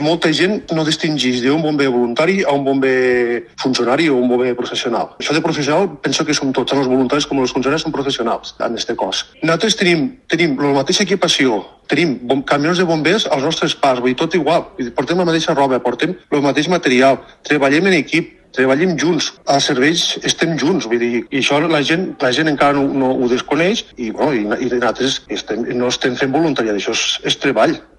[ENTREVISTA] Els bombers voluntaris alcen la veu: «no és voluntariat, estem treballant com a bombers»